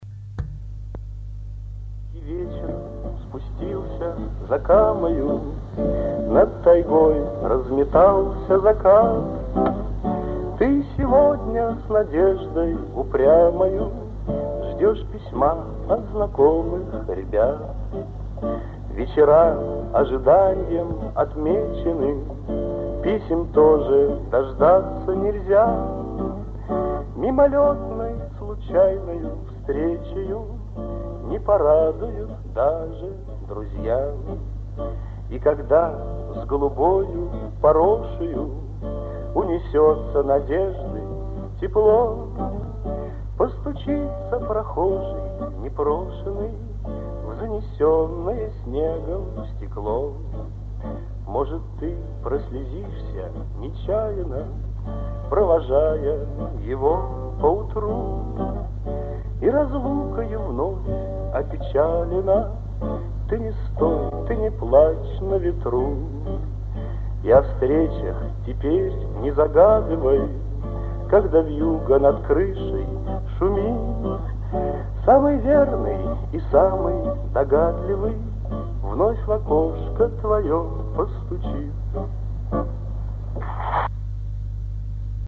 ФРАГМЕНТЫ КОНЦЕРТА-КОНКУРСА 27 АПРЕЛЯ 1959 Г. ДК МЭИ